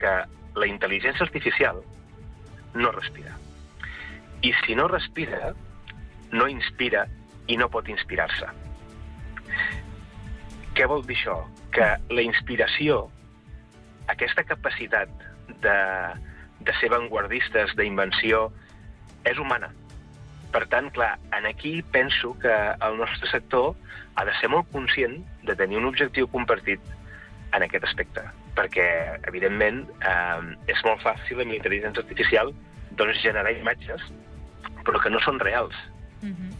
Entrevistes SupermatíSupermatí
En una entrevista concedida al Supermatí